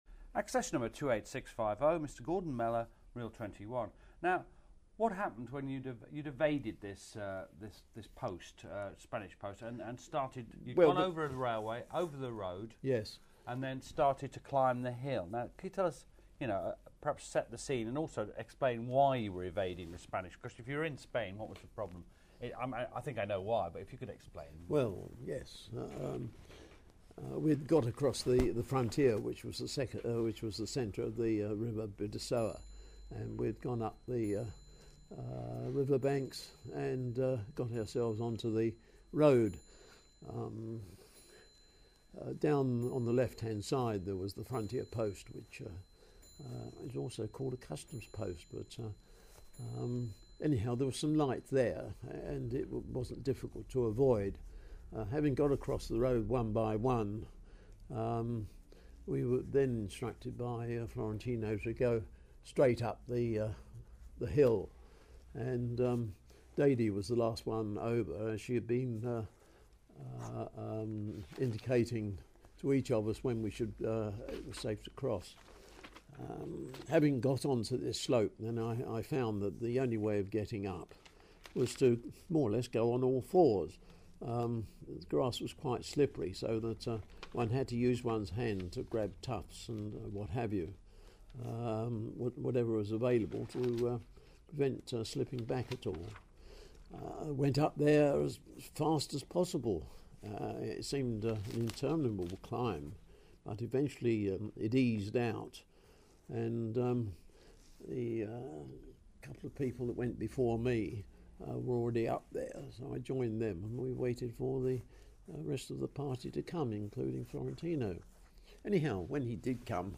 Friends of Gibraltar Oral History